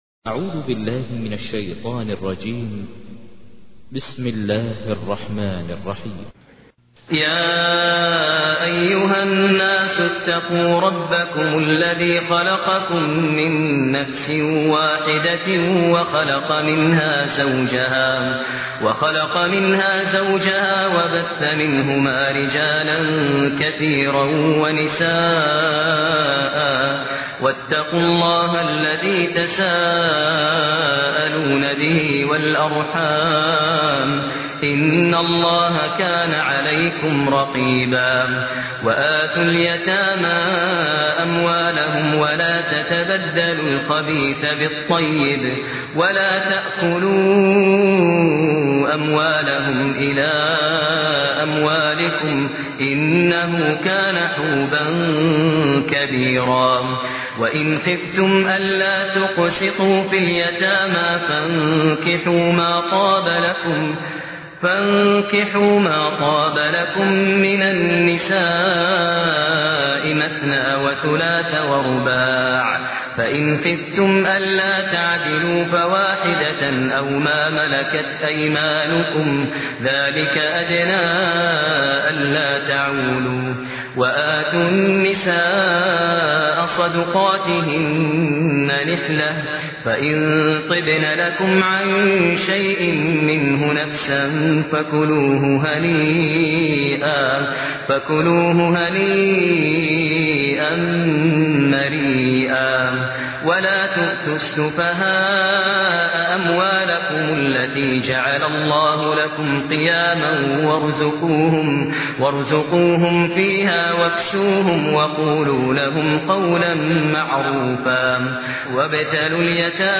تحميل : 4. سورة النساء / القارئ ماهر المعيقلي / القرآن الكريم / موقع يا حسين